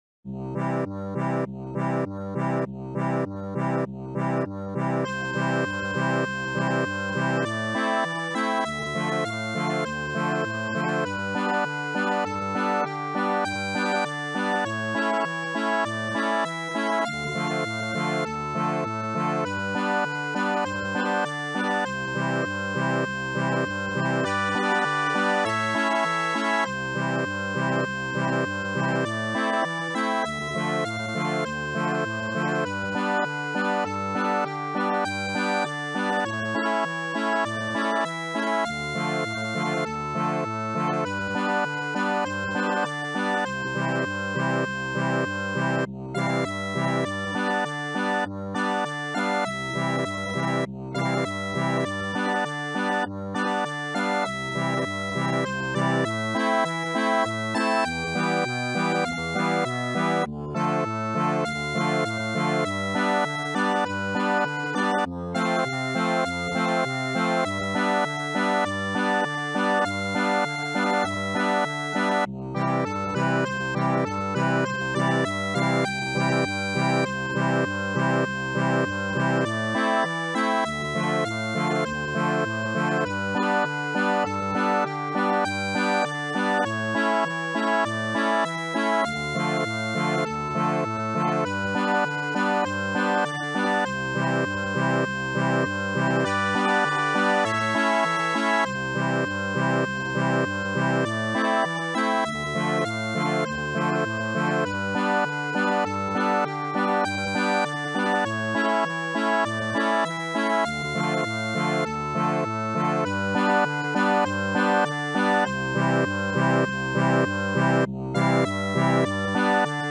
Instrument : Accordéon